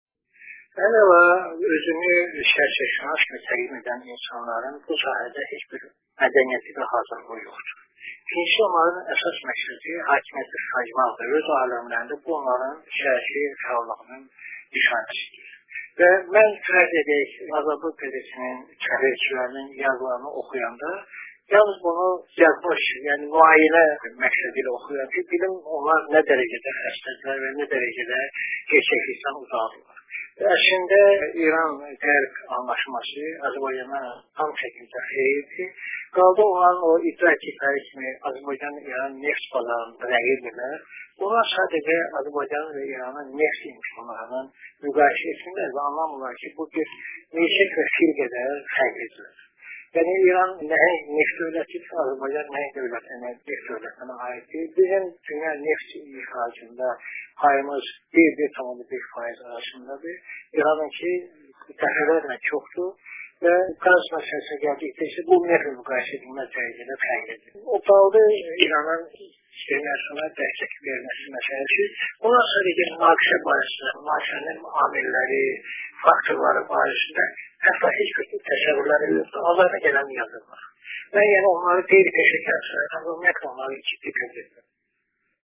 Səhər Beynəlxalq telekanalının azəri radiosu ilə eksklüziv müsahibədə